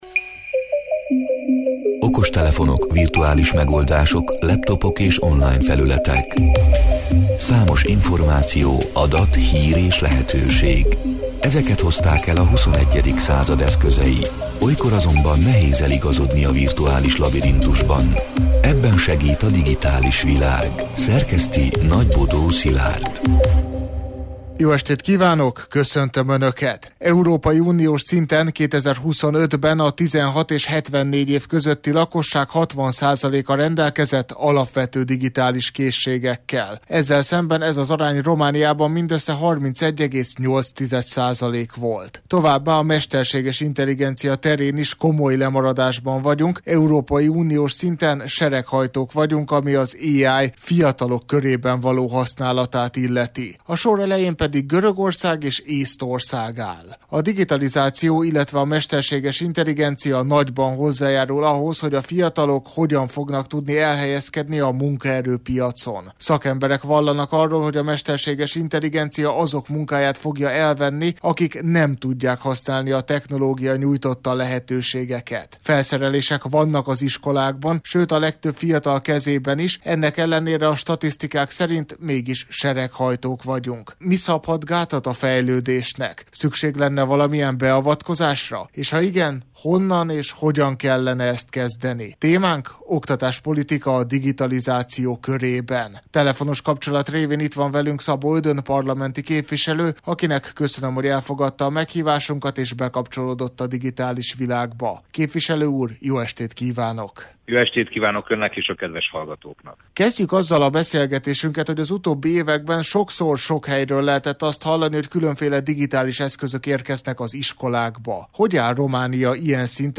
A Marosvásárhelyi Rádió Digitális Világ (elhangzott: 2026. március 17-én, kedden este nyolc órától élőben) c. műsorának hanganyaga: